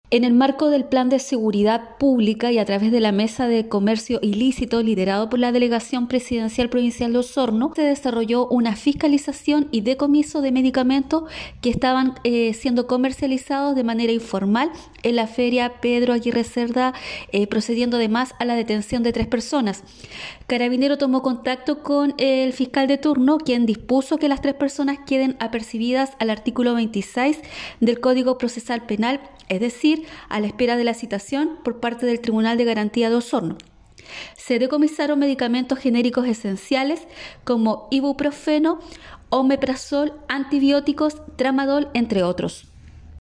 Por su parte la Delegada Presidencial Provincial de Osorno, Claudia Pailalef Montiel señaló que estas medidas son parte de la mesa que busca evitar el comercio ilícito de medicamentos, entre los que se decomisó Ibuprofeno, Omeprazol, Tramadol, entre otros.